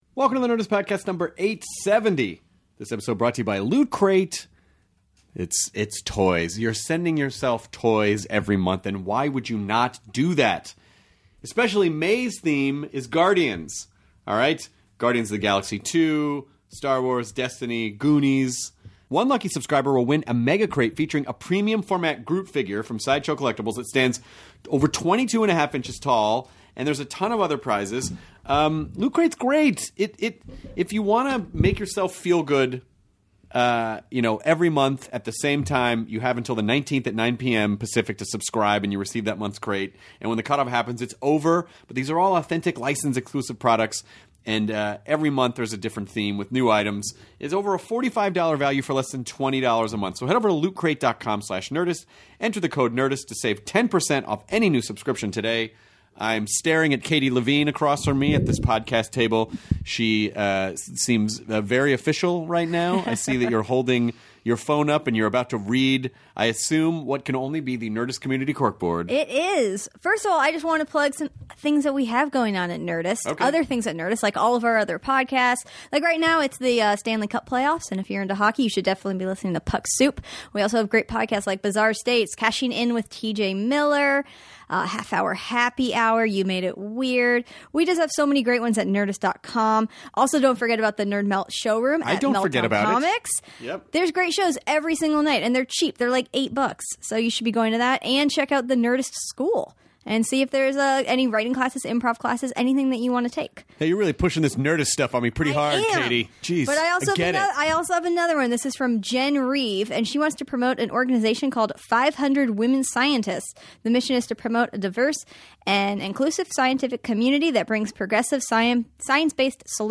Guy Ritchie (director, Snatch, Lock Stock and Two Smoking Barrels) chats with Chris about people's decision to be sober, the importance of being true to yourself and not holding yourself back. They also talk about King Arthur: Legend of the Sword and where the inspiration came from for how Guy wanted to tell the story!